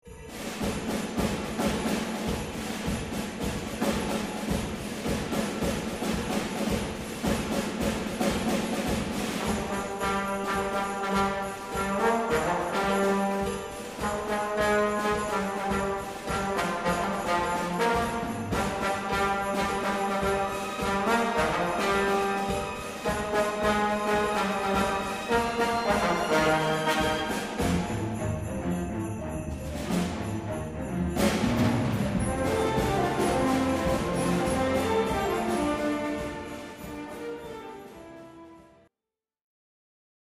Orchestre seul